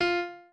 pianoadrib1_15.ogg